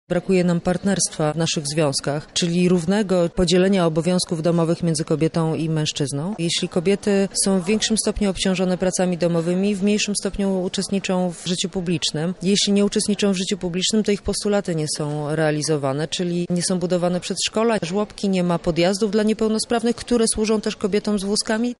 Pod takim hasłem odbył się w sobotę V Kongres Kobiet Lubelszczyzny.
Sytuacja kobiet się zmienia, ale wciąż mamy do zrobienia wiele rzeczy – mówi minister Joanna Mucha, gość specjalny kongresu